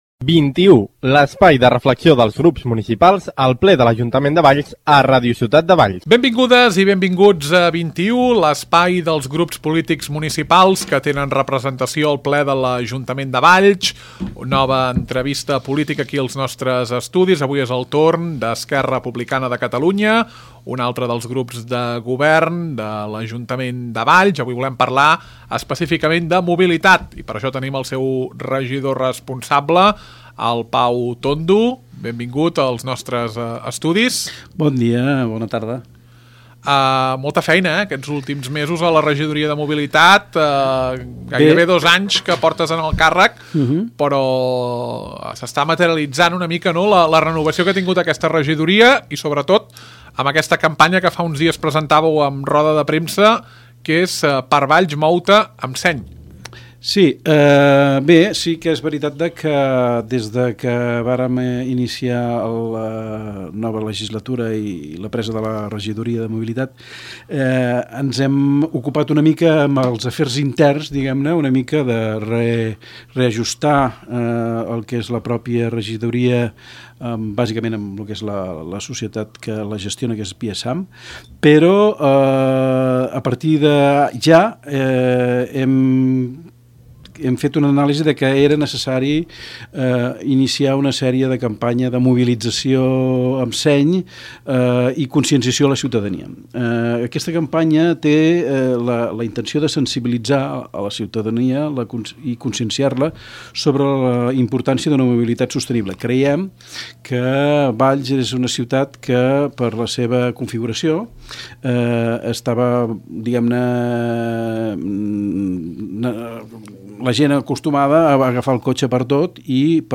Parlem amb el regidor de Mobilitat.